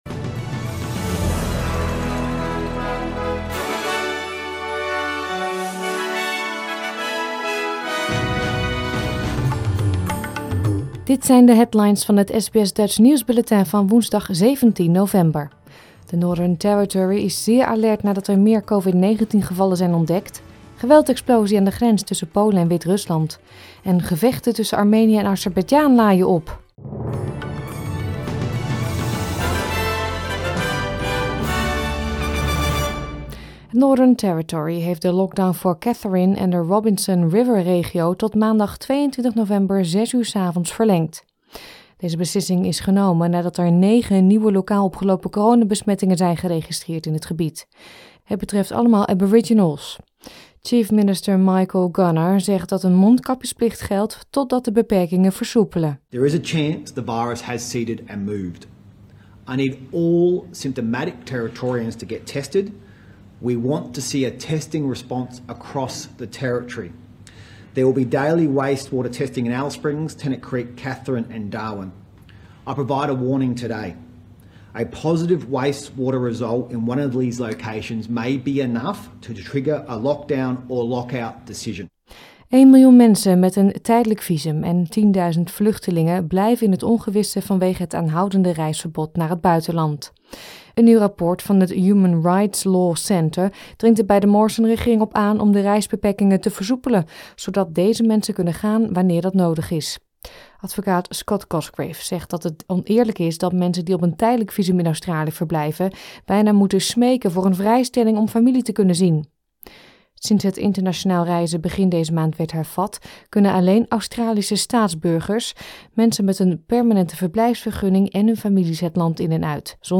Nederlands / Australisch SBS Dutch nieuwsbulletin van woensdag 17 november 2021